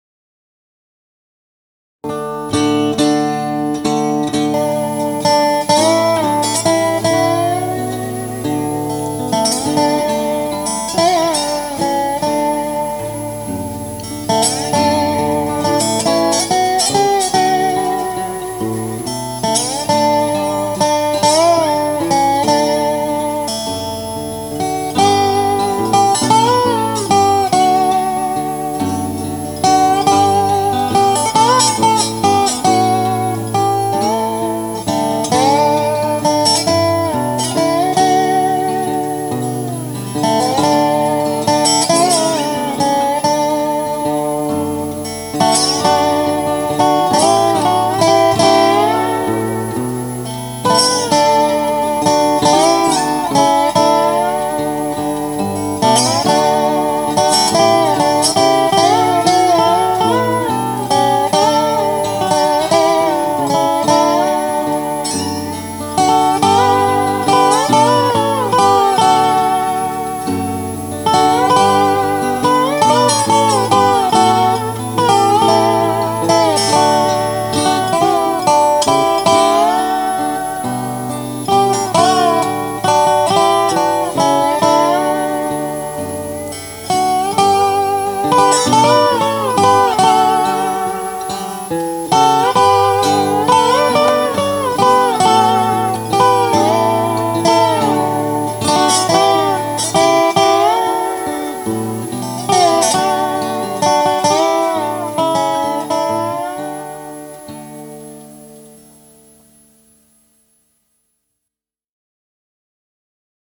Instrumental version of a song collected by Carl Sandburg in Missouri.
He apparently called it The Sad Song, which indeed it is.
Guitar and resonator guitars are all me.
Posted in Instrumental